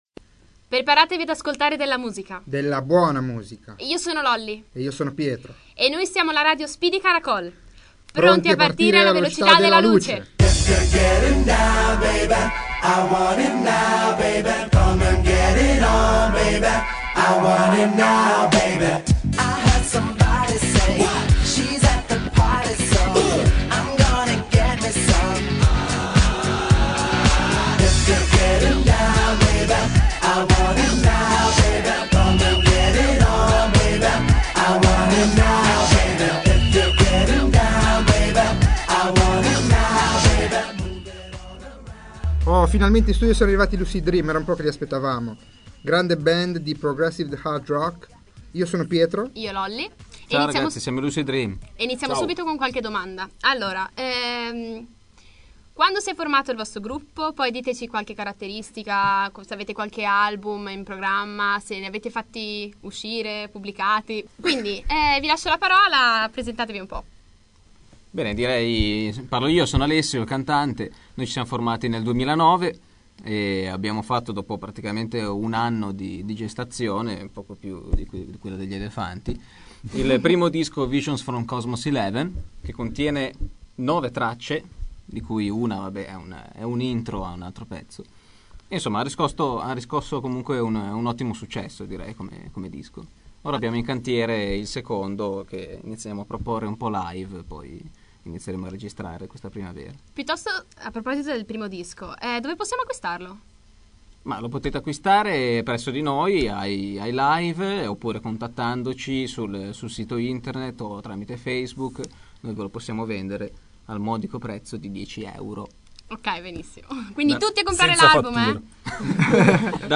Speedy Caracol: Ospite la band Progressive Hard Rock LuciD DreaM